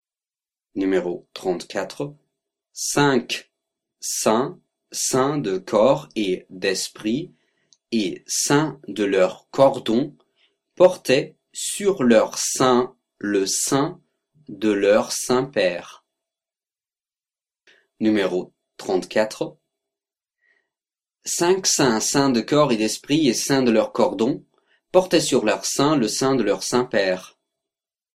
34 Virelangue